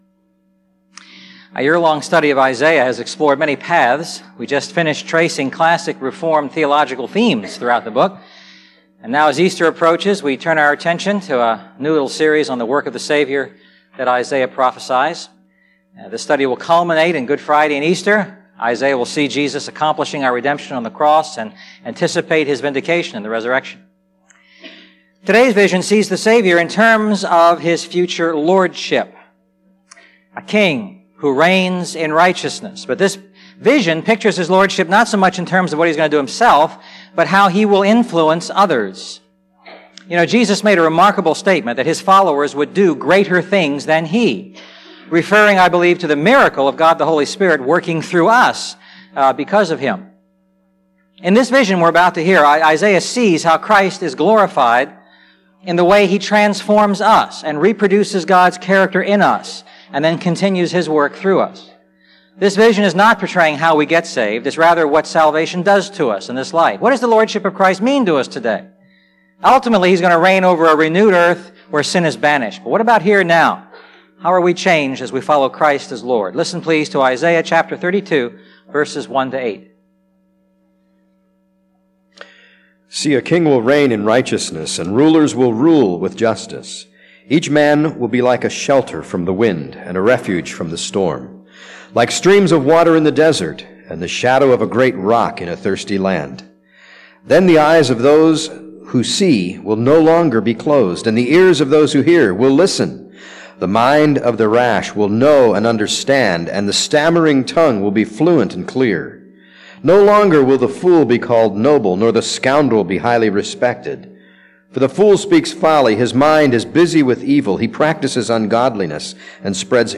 A message from the series "The Lord Saves."